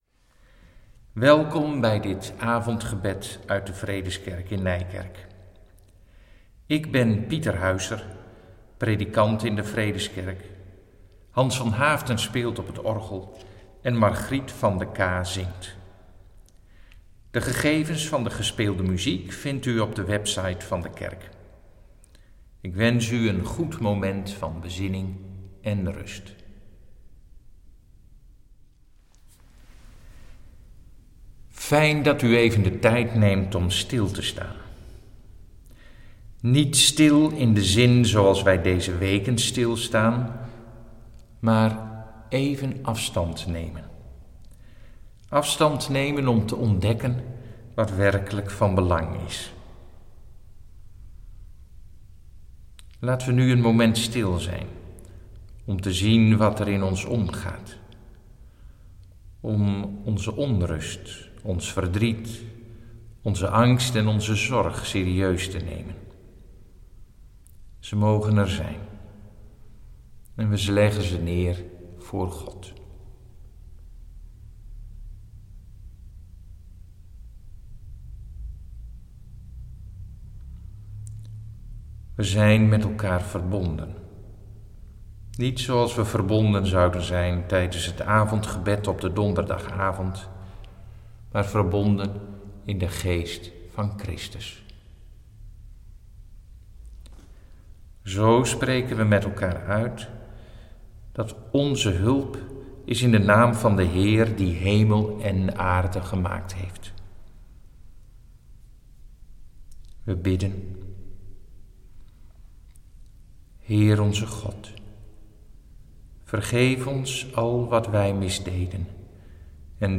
Zij zullen van tevoren opgenomen worden.
We lezen uit Johannes 8: 46-59. Ik spreek een meditatie uit.
Na een gebed sluiten we af met Lied 246b: 1, 2 en 3.